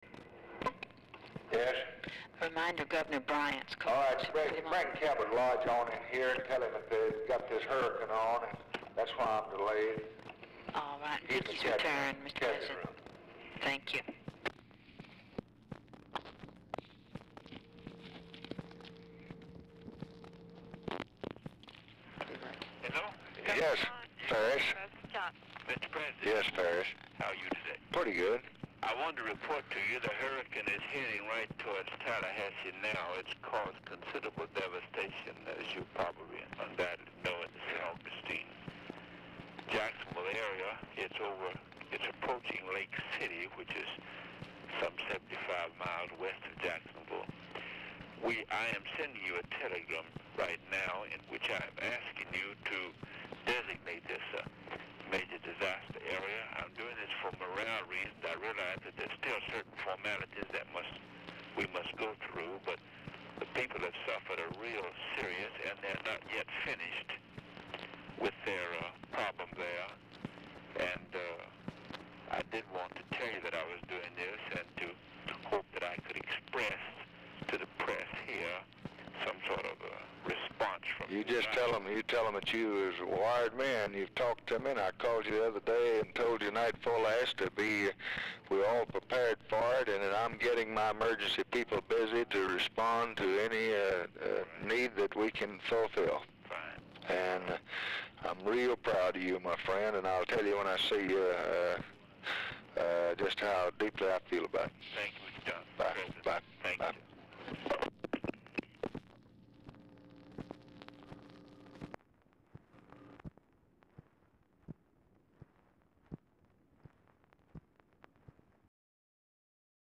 Telephone conversation # 5570, sound recording, LBJ and FARRIS BRYANT, 9/10/1964, 12:12PM | Discover LBJ
Format Dictation belt
Specific Item Type Telephone conversation